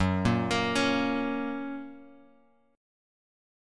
F# chord {2 1 x 3 2 x} chord
Fsharp-Major-Fsharp-2,1,x,3,2,x-8.m4a